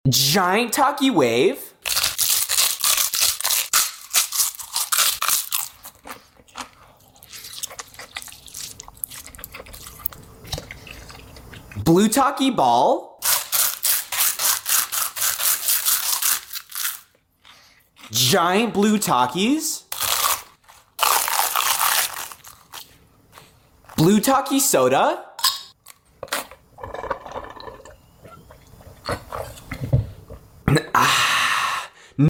Giant Blue Takis ASMR!? 🥵 sound effects free download